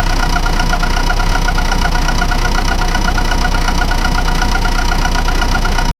b-box-car-engine---ynyqehcc.wav